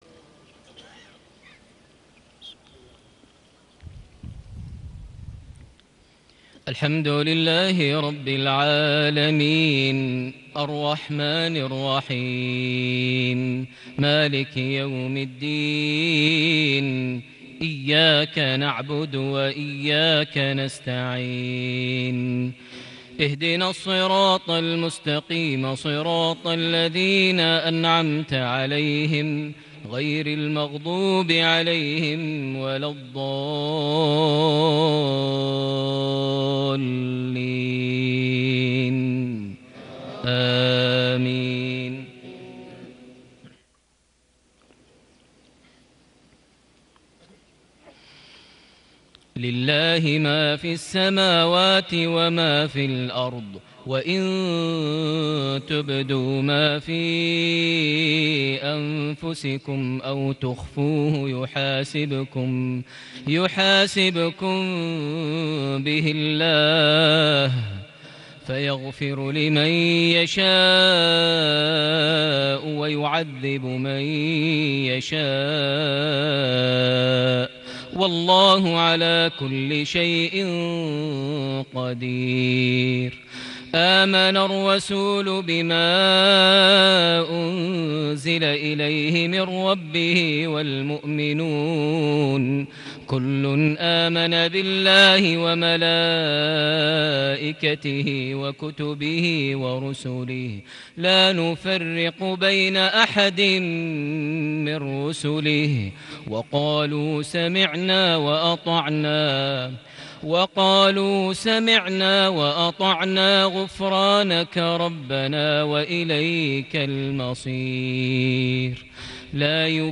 صلاة المغرب ٢٣ربيع الآخر ١٤٣٨هـ خواتيم سورة البقرة / الإخلاص > 1438 هـ > الفروض - تلاوات ماهر المعيقلي